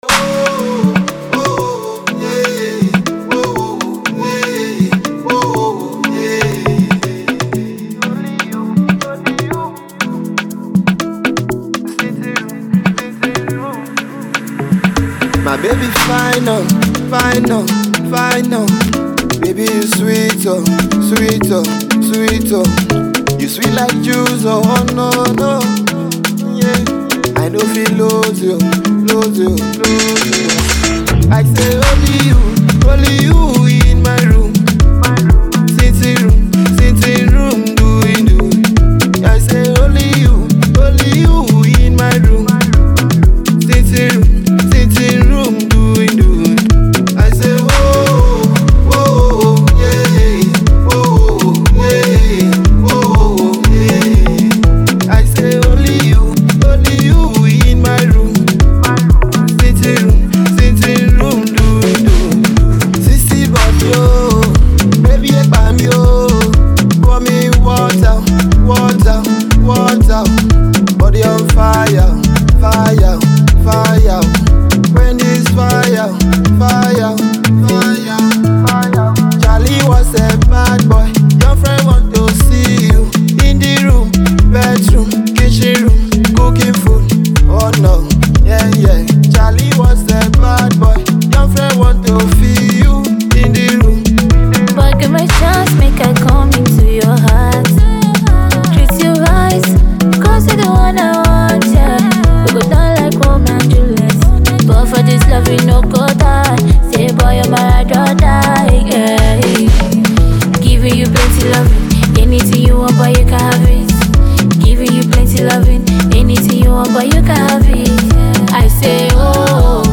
The Mid Tempo groovy jam
female singer